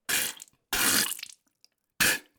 SFX吐血02(Spitting Blood 02)音效下载
SFX音效